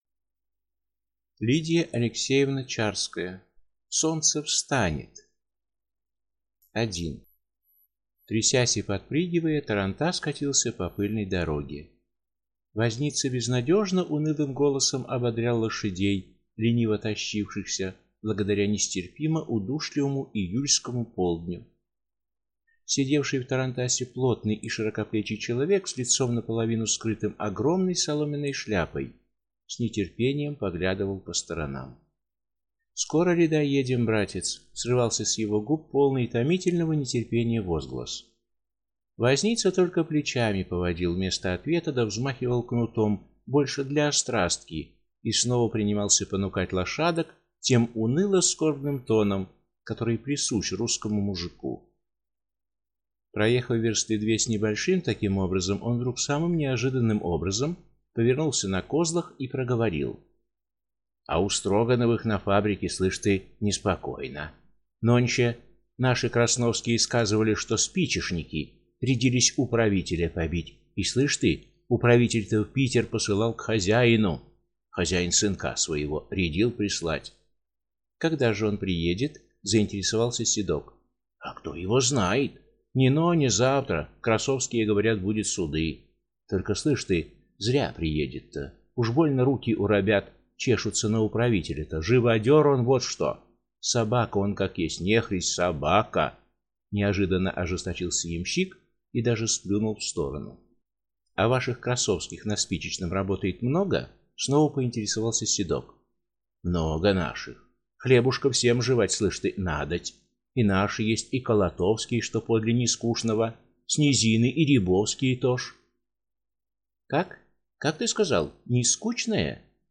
Аудиокнига Солнце встанет!
Прослушать и бесплатно скачать фрагмент аудиокниги